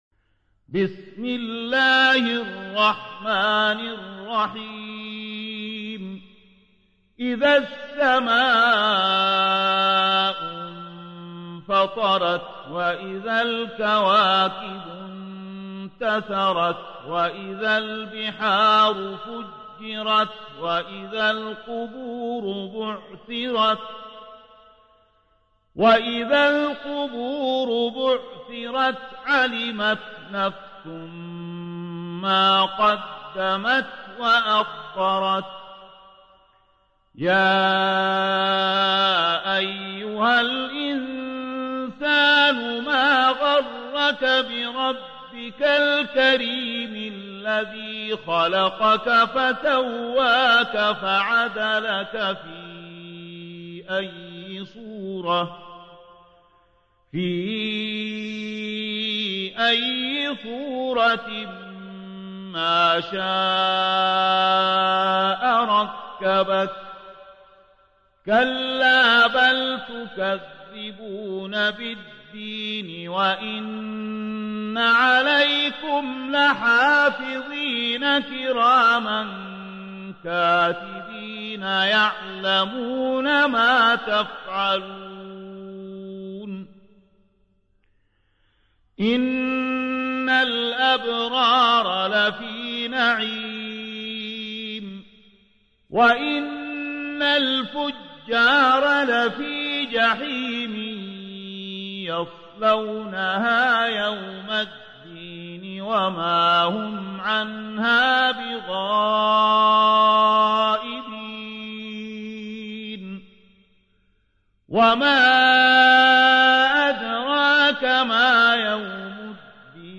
تحميل : 82. سورة الانفطار / القارئ زكي داغستاني / القرآن الكريم / موقع يا حسين